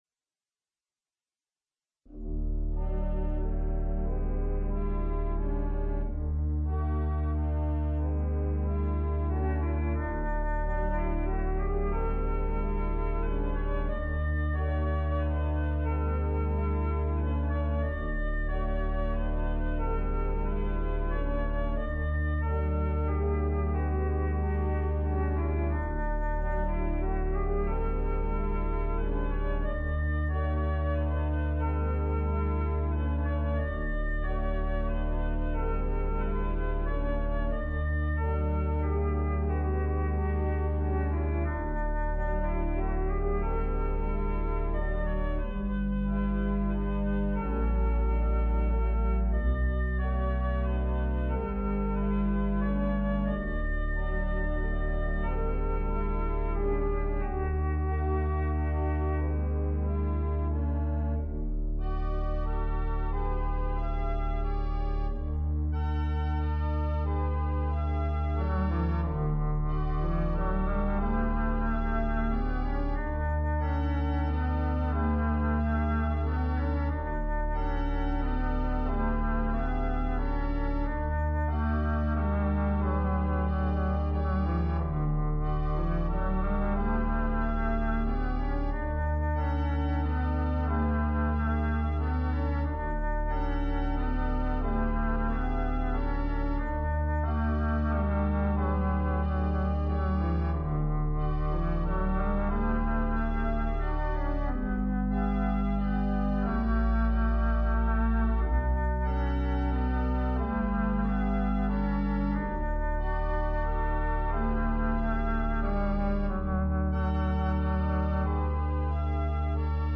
Minimal pedal technique required (4 notes).
Christmas